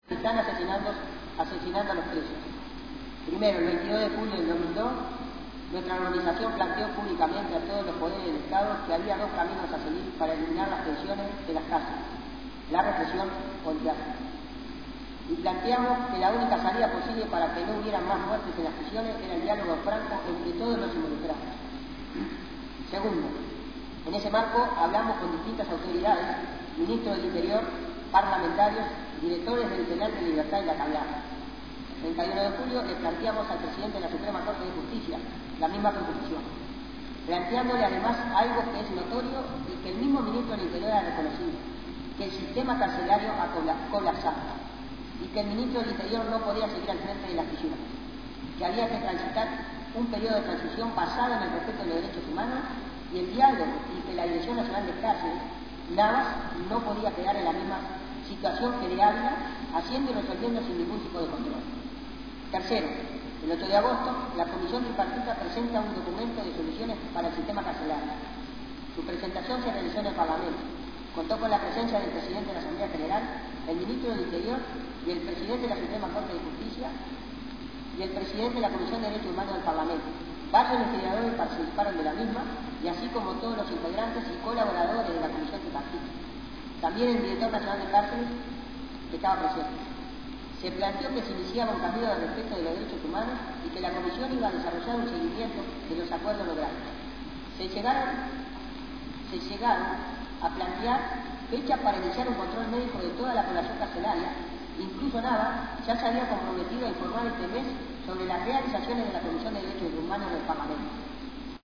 Comenzó leyendo un comunicado emitido por Familiares de Presos en Lucha y el Comité por la Libertad de los Muchachos Presos. Concluyó desarrollando ampliamente la situación que viven los presos sociales en los campos de concentración, especialmente en las ruinas del Penal de «Libertad».